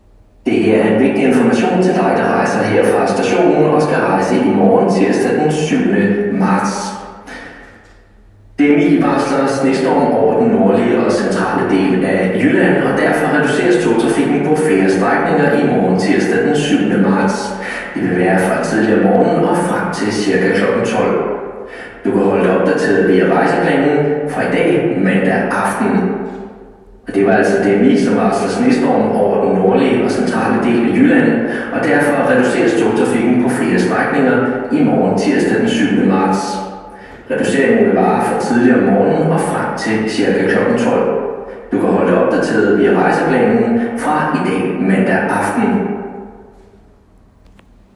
Stationslyde inkl. højttalerudkald